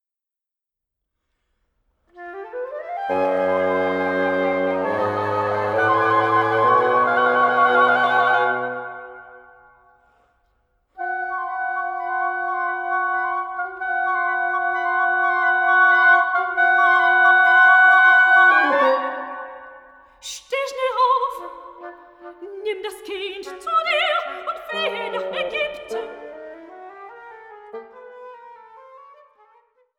Sopran
Flöte, Altflöte
Oboe, Englischhorn
Bassetthorn
Fagott